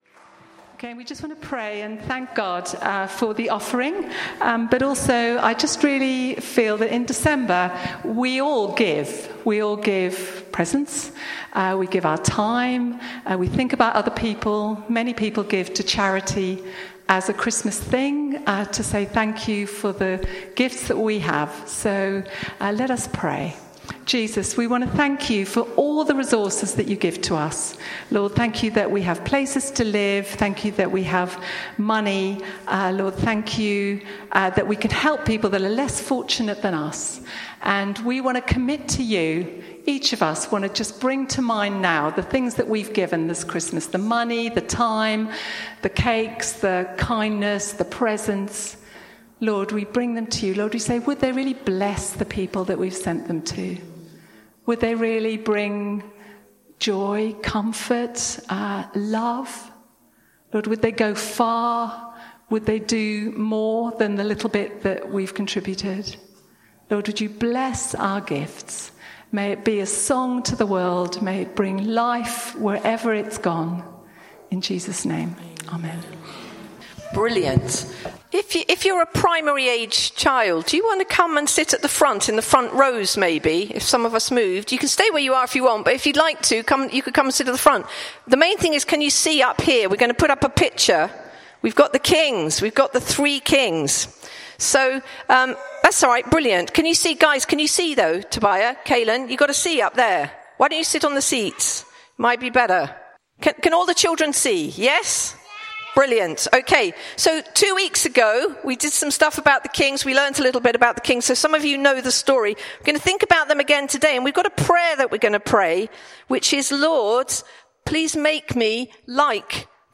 Wise Men still seek Him, Bassett Street Sermons